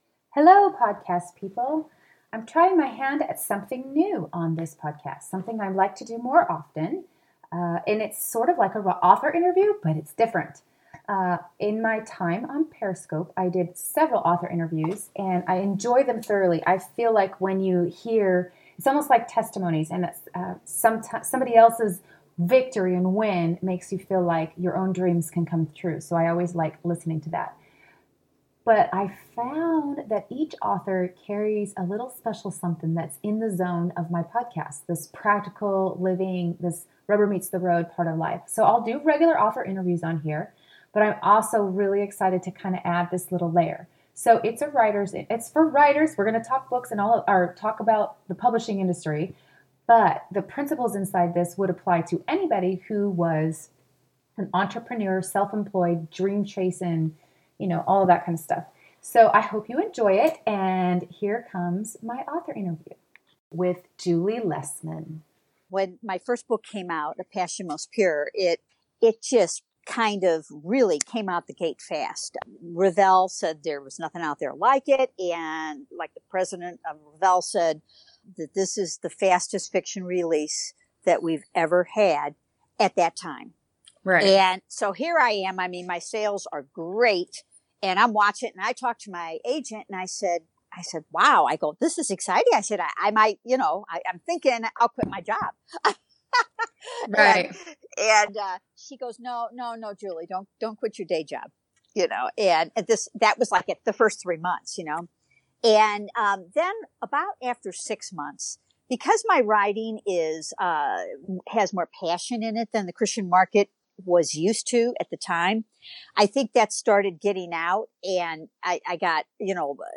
This is a raw, serious discussion on truths I don’t admit to just everybody. But she — and I — wanted to help writers understand what happens AFTER you get published.